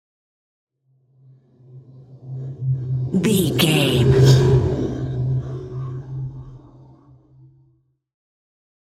Scifi whoosh pass by deep
Sound Effects
futuristic
pass by